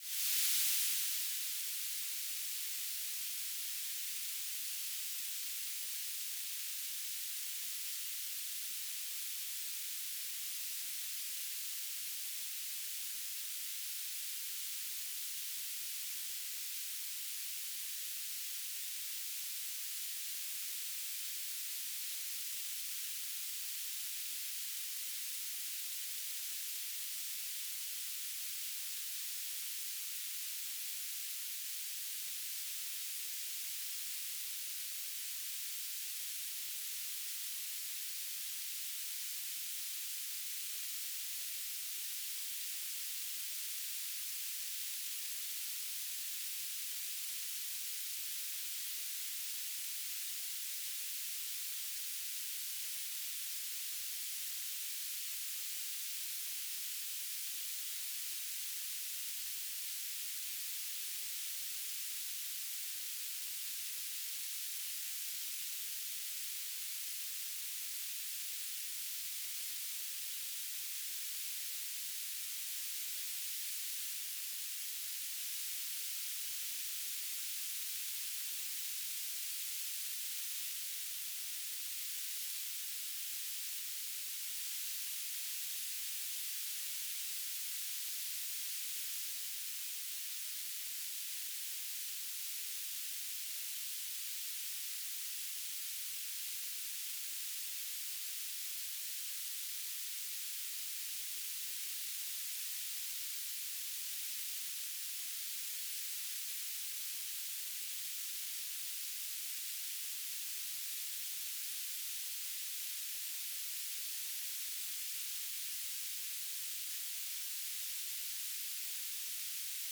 "transmitter_description": "UHF Unknown",
"transmitter_mode": "BPSK",